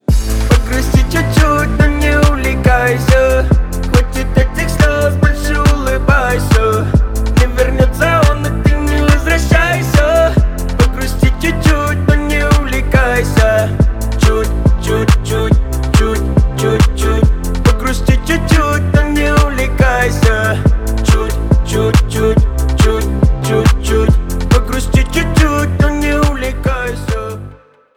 Поп Музыка
весёлые # клубные